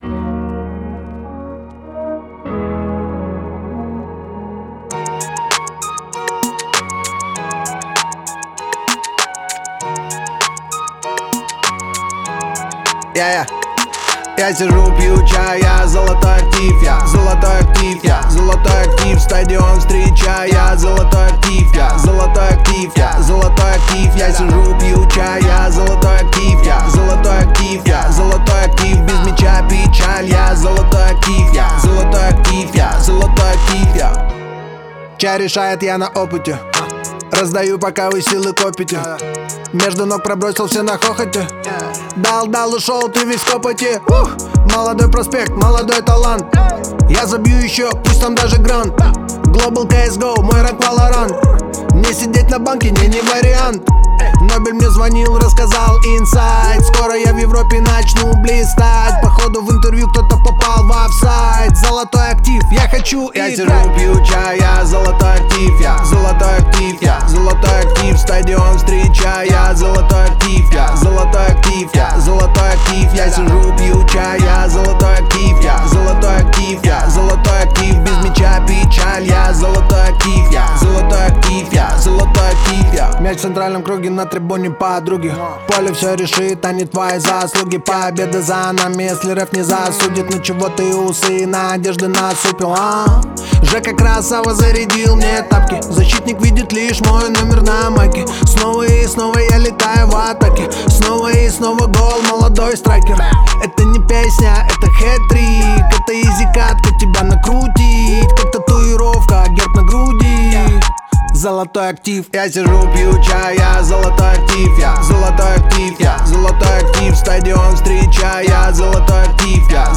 яркая и динамичная песня